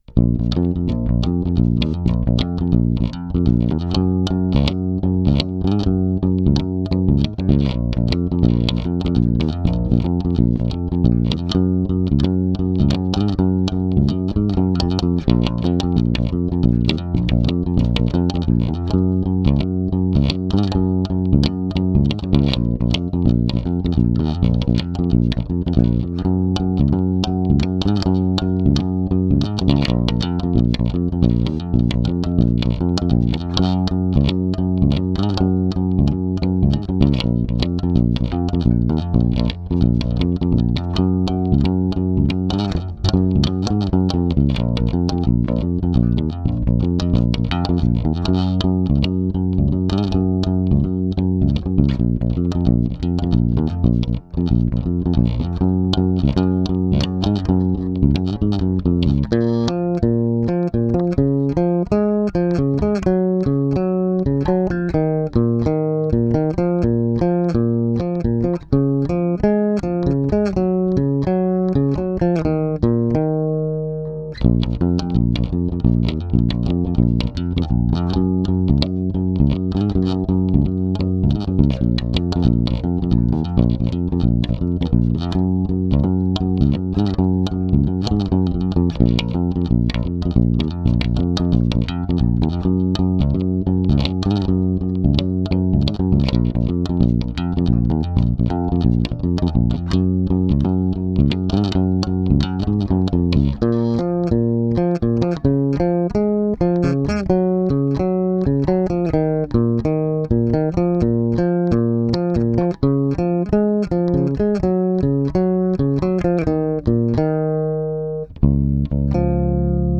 (bass only)
Cover / Instrumental